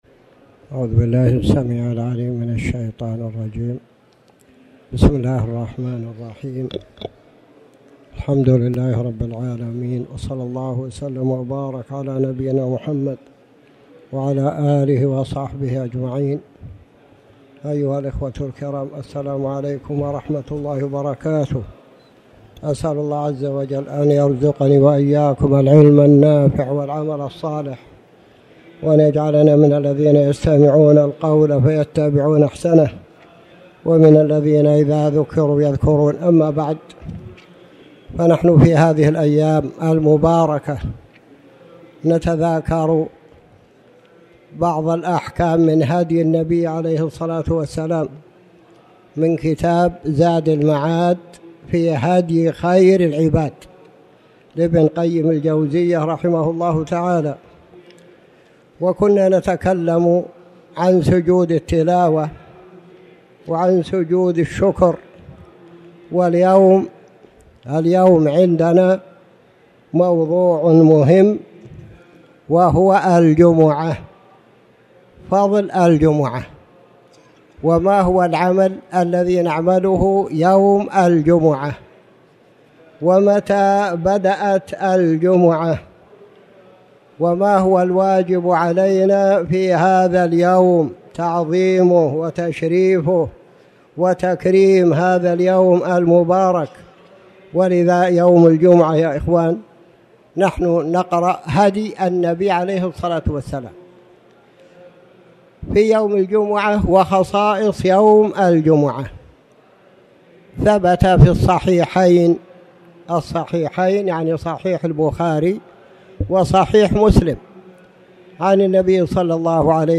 تاريخ النشر ١٧ ذو الحجة ١٤٣٩ هـ المكان: المسجد الحرام الشيخ